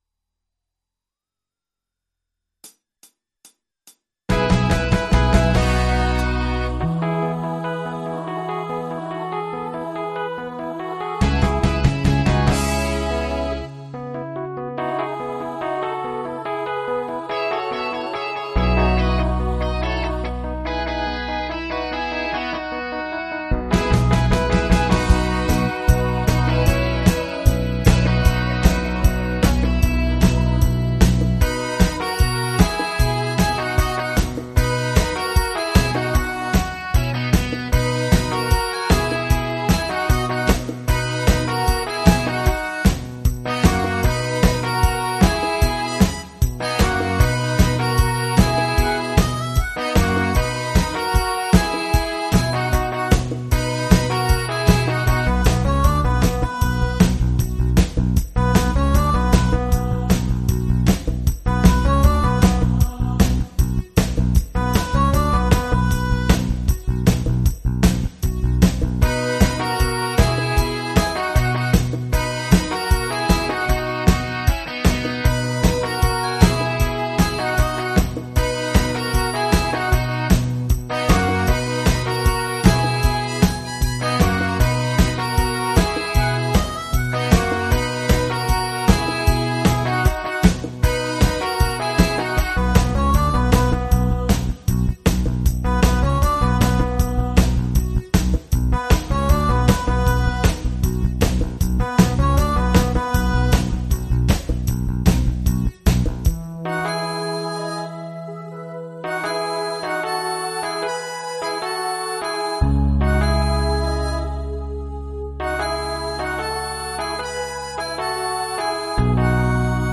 PRO MIDI Karaoke INSTRUMENTAL VERSION